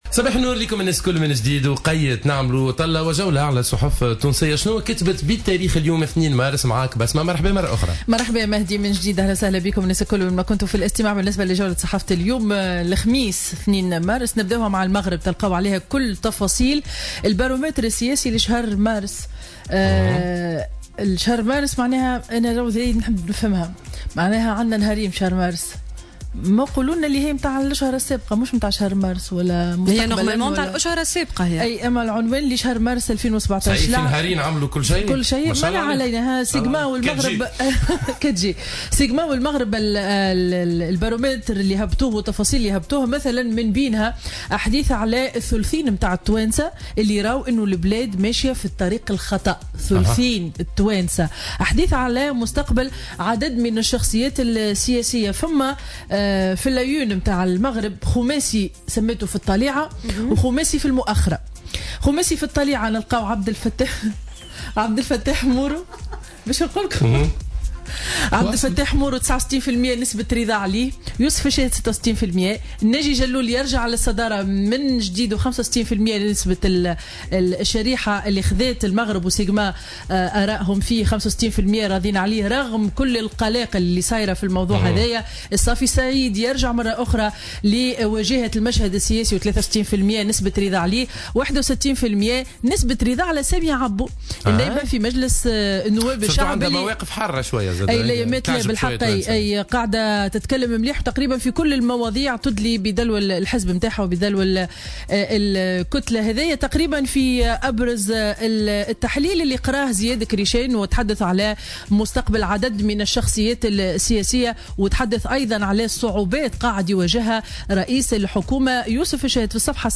Revue de presse du jeudi 2 mars 2017